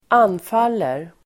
Uttal: [²'an:fal:er]